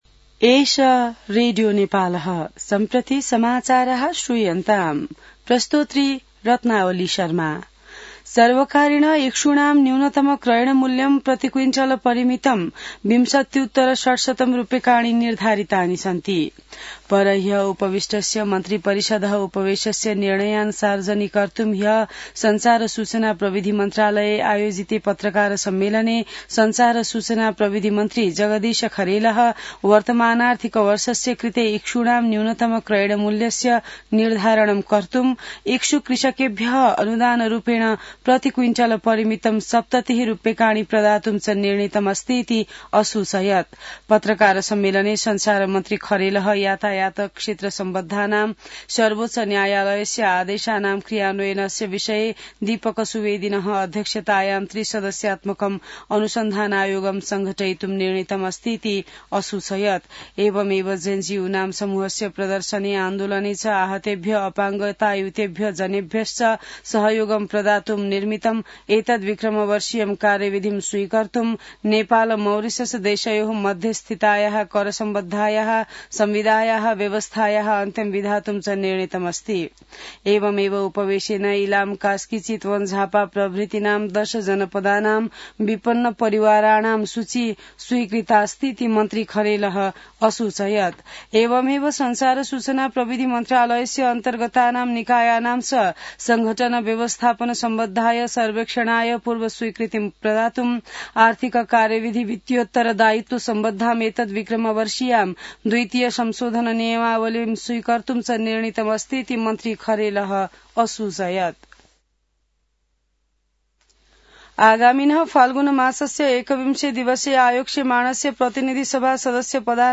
संस्कृत समाचार : २४ मंसिर , २०८२